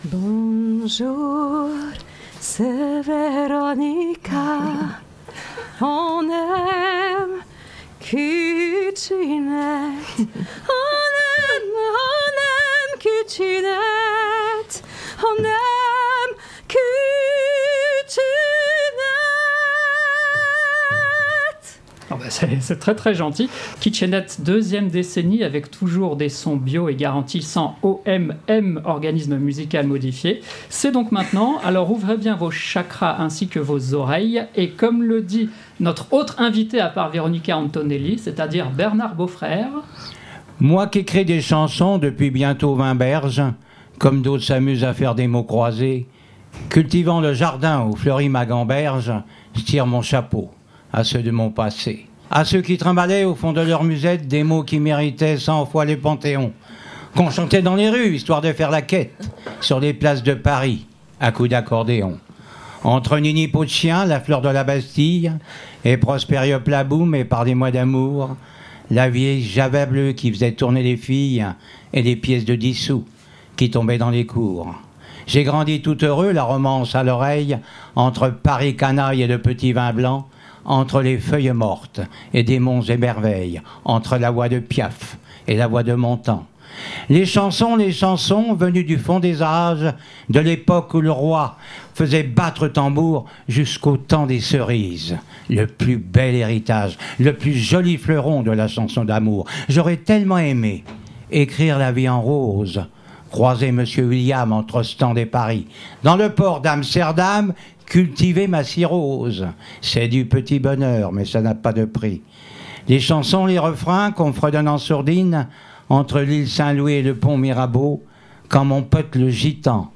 Le direct du 16 décembre 2021